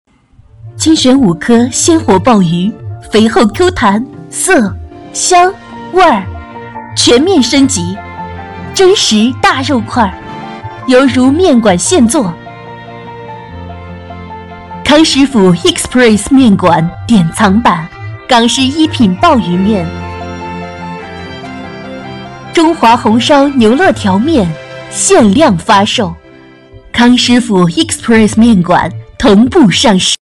女155-美食广告_音频
女155-美食广告-音频.mp3